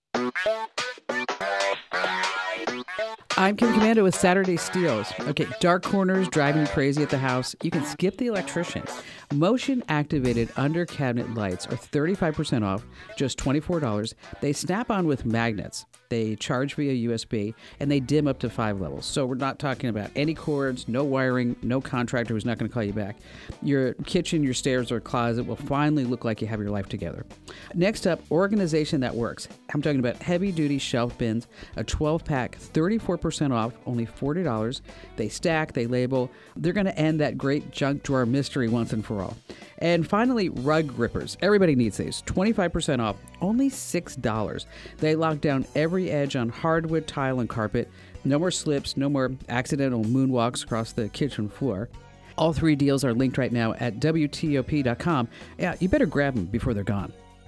Kim Komando breaks downs some of the top ‘Saturday Steals’